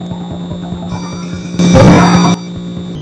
Here is a subliminal I had intercepted coming down the powerline.
It can be paranormal spooky at times.